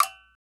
TS Perc 5.wav